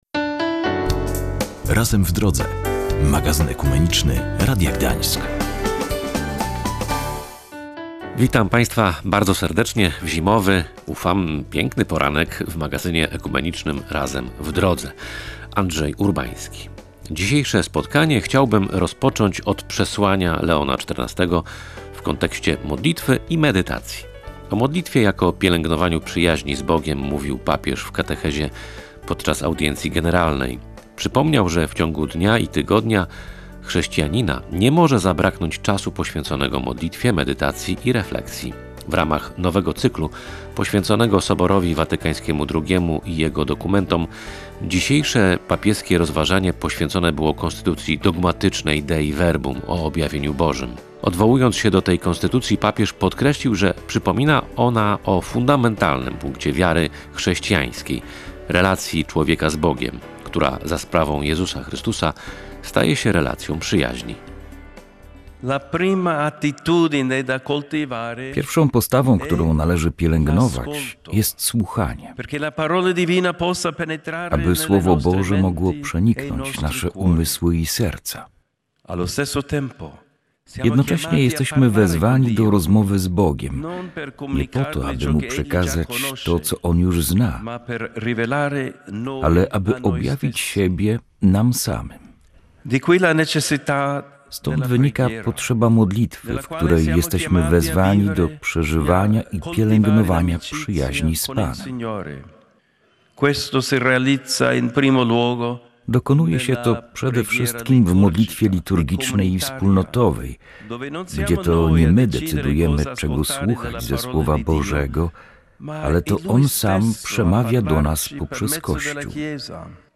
Porozmawialiśmy z przedstawicielami zarówno kościoła prawosławnego, jak i Ewangelicznego Kościoła Reformowanego.